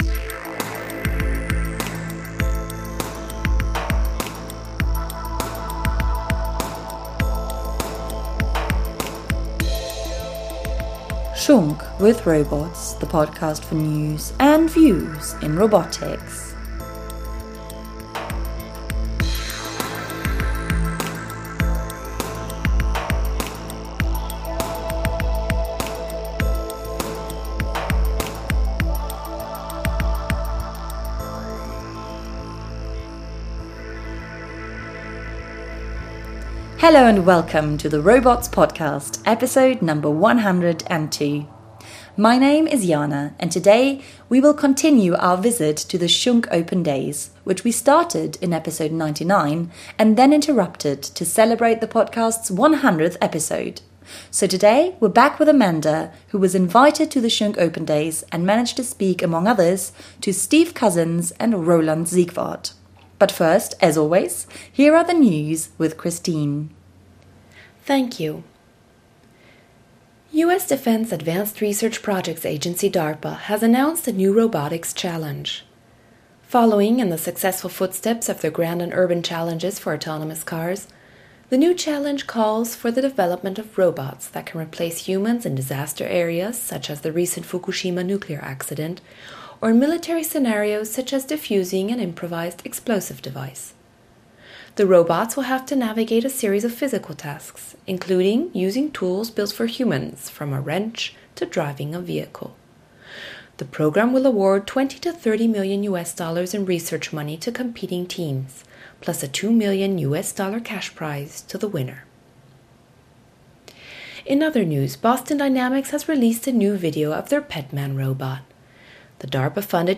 Our interviews bring to surface the dynamic interplay of academia and industry – we talk about the transition from the research lab to the market. Listen in and find out which robotic applications are bound to make great breakthroughs soon!